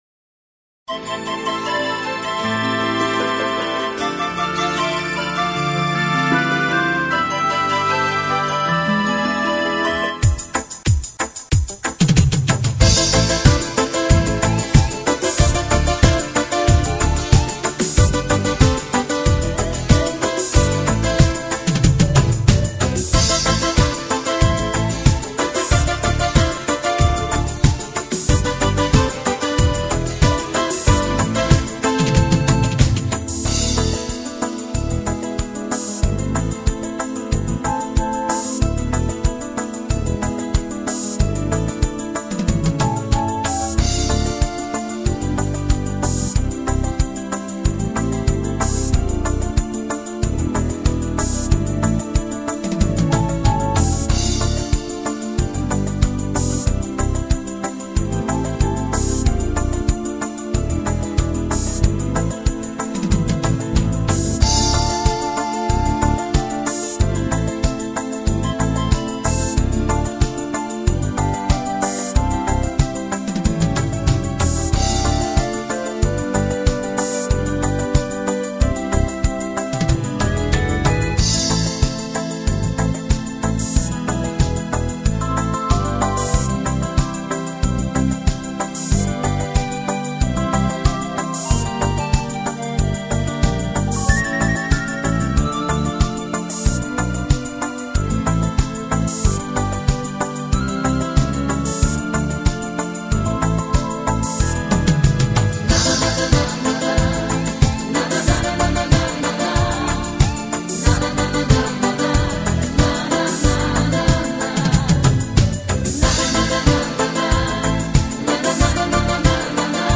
. хоть это КАРАОКЕ подойдет?